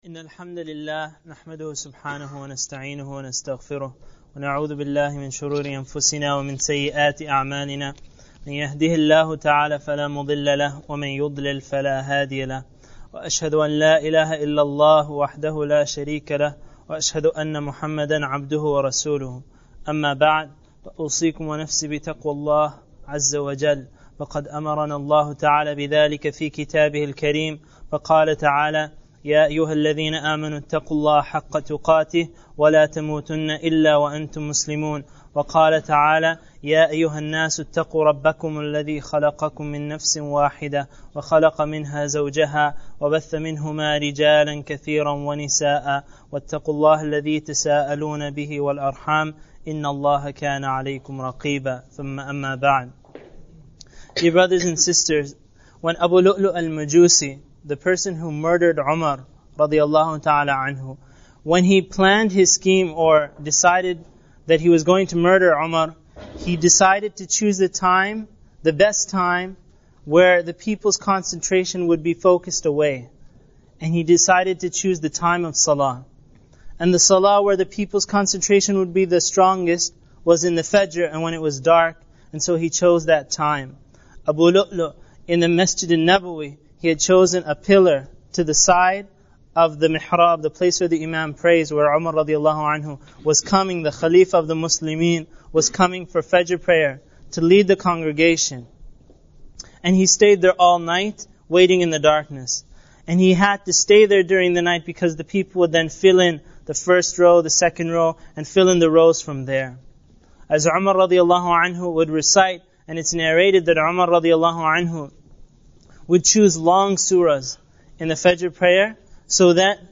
A lecture in English in which the lecturer explains the virtue of prayer and narrates the story of t